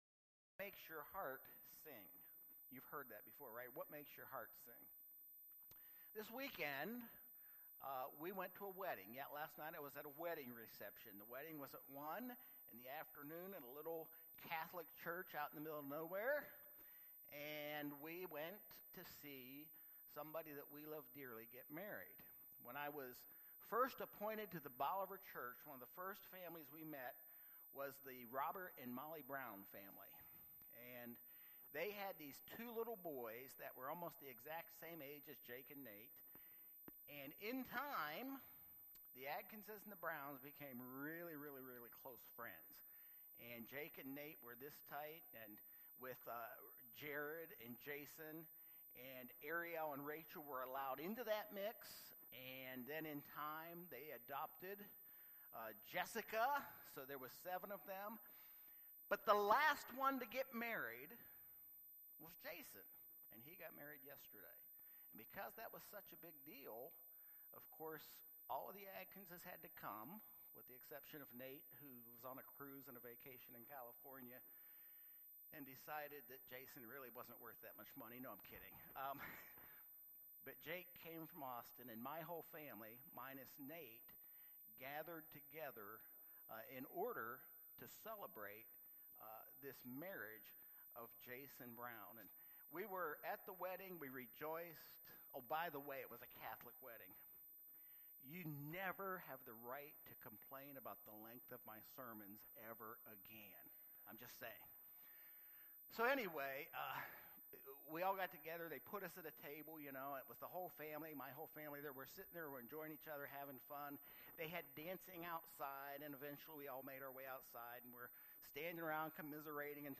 Sermons by Westbrook Park United Methodist Church